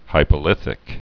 (hīpə-lĭthĭk)